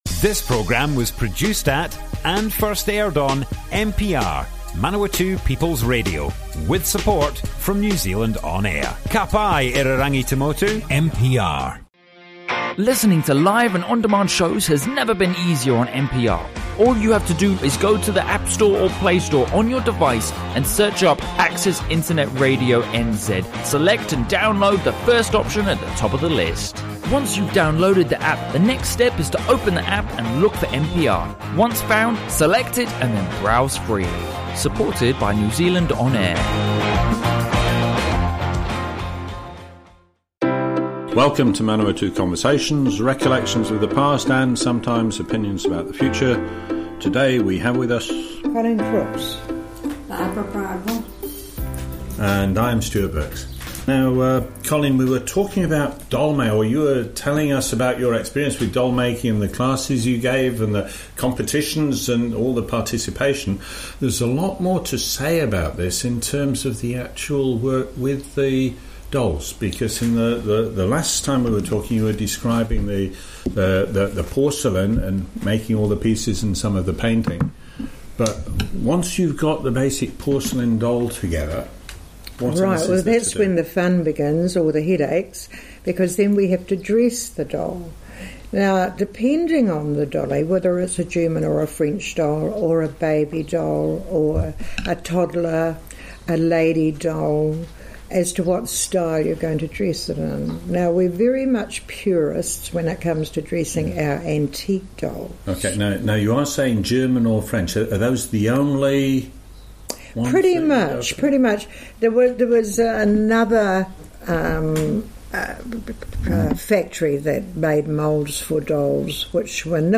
Broadcast on Manawatu People's Radio 22 January 2019.